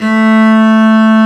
Index of /90_sSampleCDs/Roland L-CD702/VOL-1/STR_Cb Bowed/STR_Cb3 Arco nv
STR CELLO 05.wav